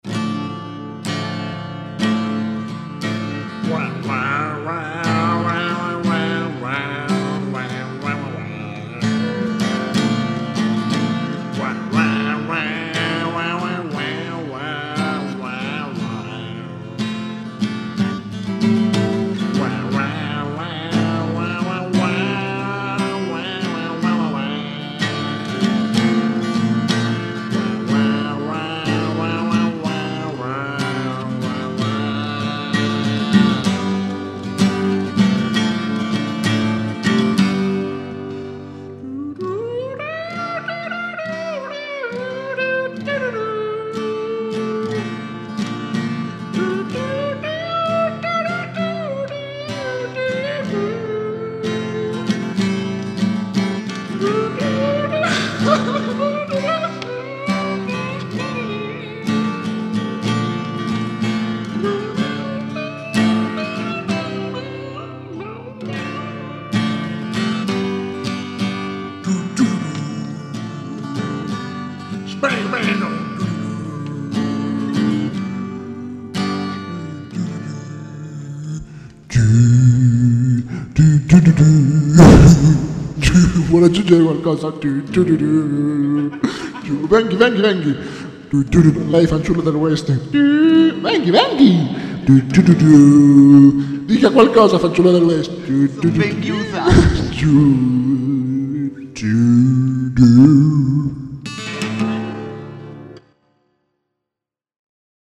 Il lamento del coyote (strumentale alla bona)